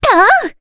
Cassandra-Vox_Attack2_jp.wav